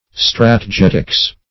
Strategetics \Strat`e*get"ics\, n.